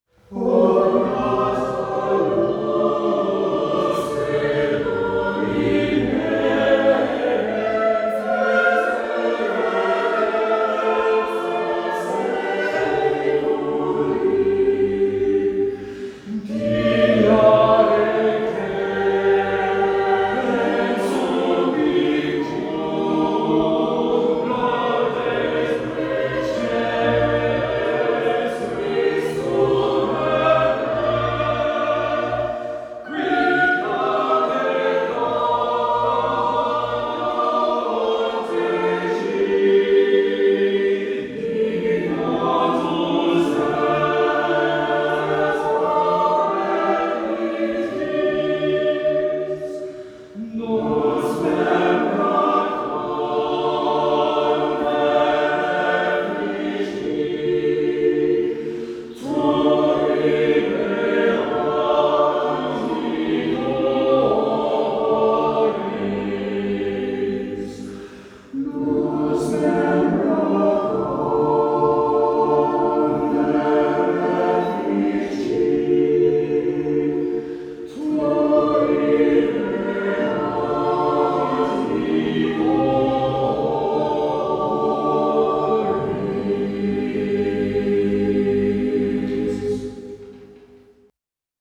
The choir lend musical support to all the major services as well as holding dedicated concerts in the church from time to time.  Although a small band, they perform a variety of choral works, often with choirs from other churches.
Listen to our Choir singing ‘O Natus Lux’ by Thomas TallisDownload